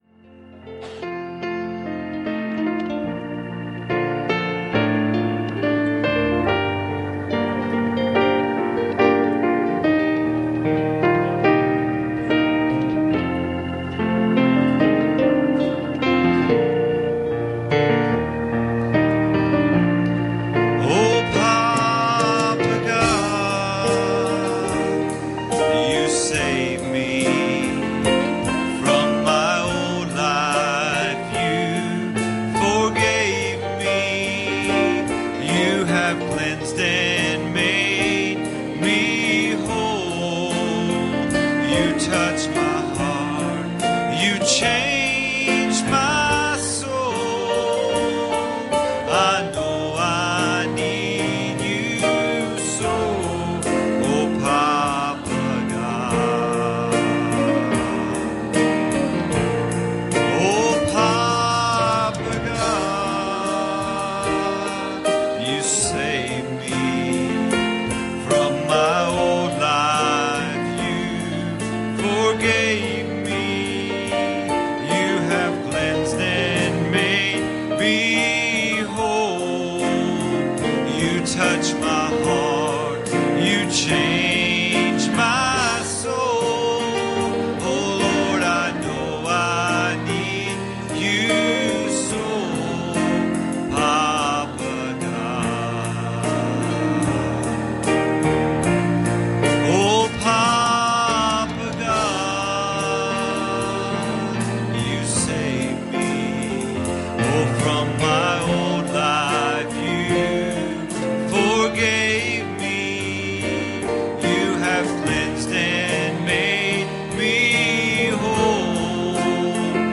Passage: John 3:3 Service Type: Wednesday Evening